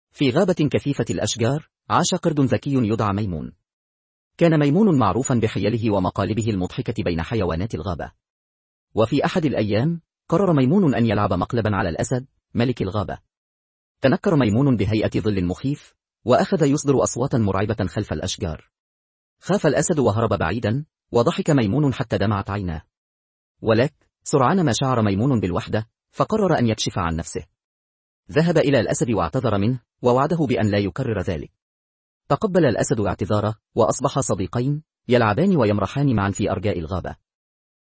أمثلة لنتائج مولد الأصوات المجاني المتقدم VocalAI
لاحظ تغير نغمة نفس المعلق لتصبح أكثر حدة وحيوية، وذلك بظبط النغمة على (12).